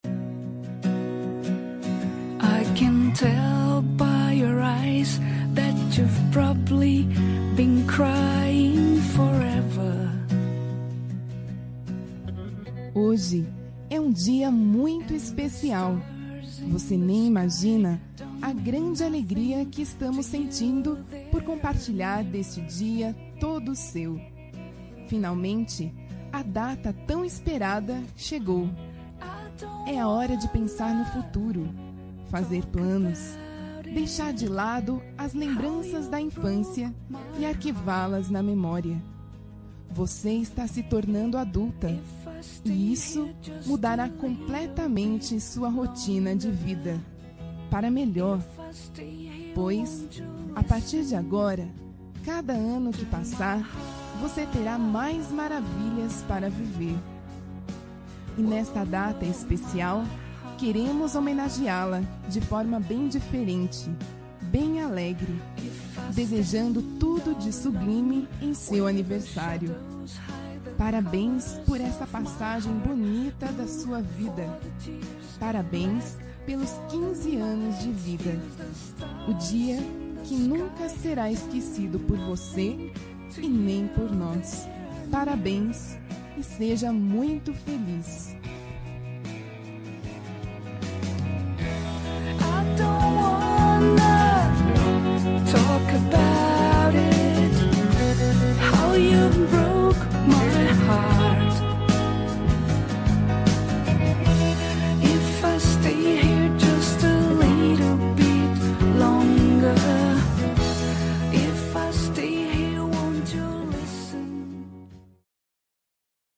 Aniversário de 15 anos – Voz Feminina – Cód: 33372